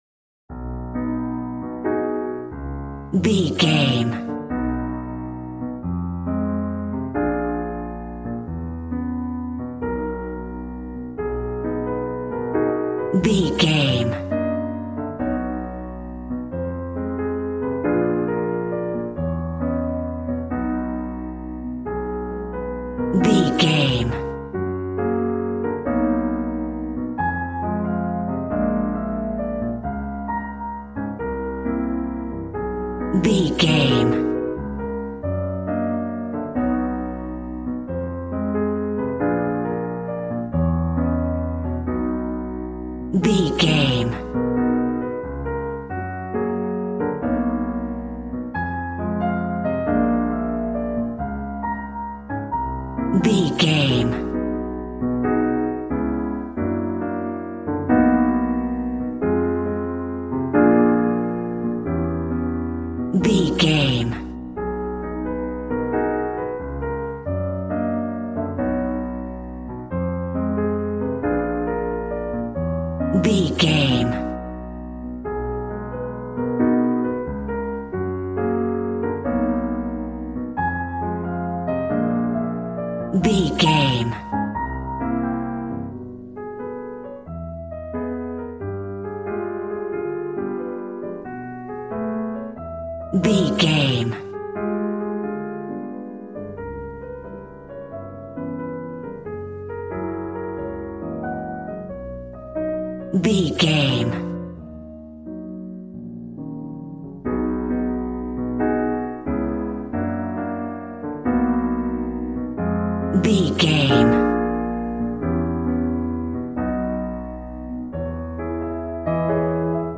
Smooth jazz piano mixed with jazz bass and cool jazz drums.,
Aeolian/Minor
E♭
smooth
piano
drums